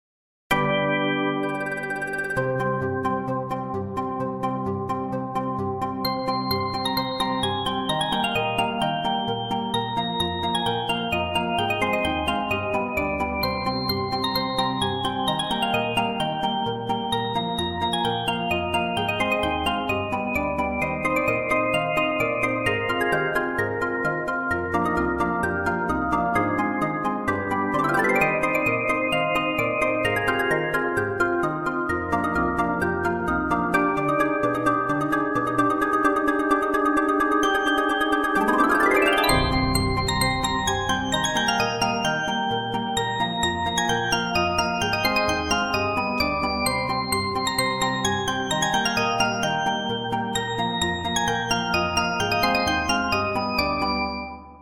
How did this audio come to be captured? App used is Midi Playground